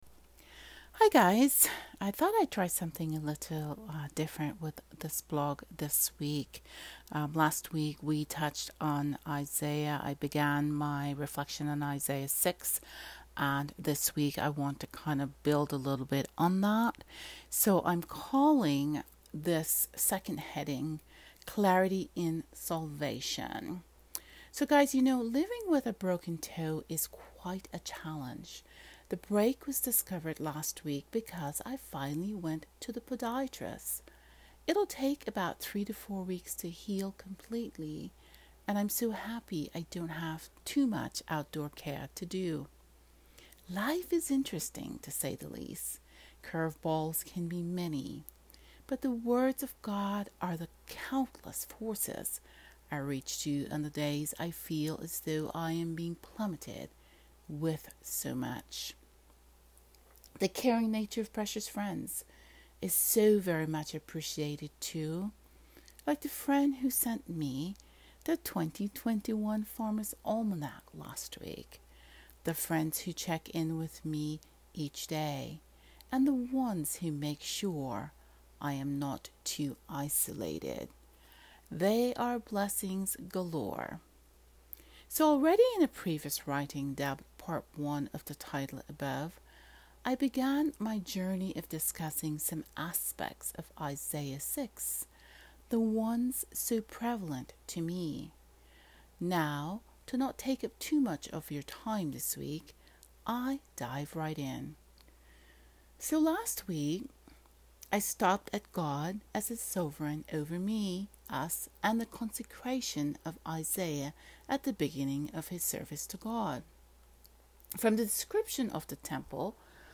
Listen to me read this reflection: